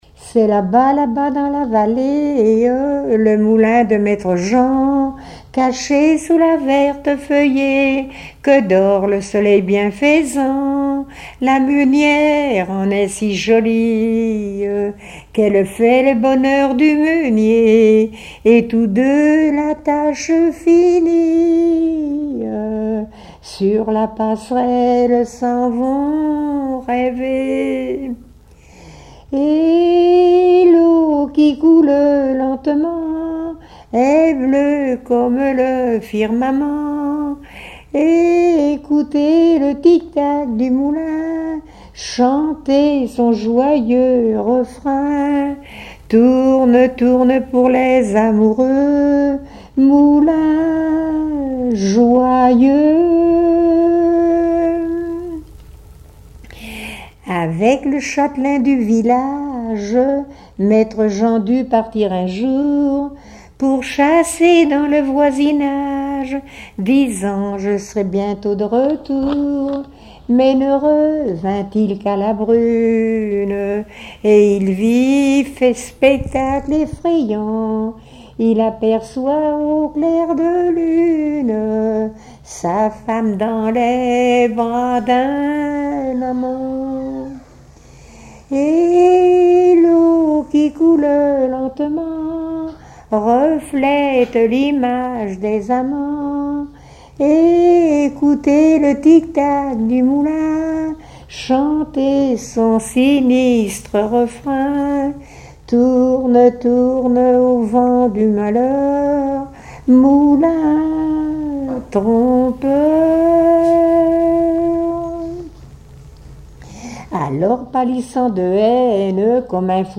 Genre strophique
Témoignages d'une couturière et chanson
Catégorie Pièce musicale inédite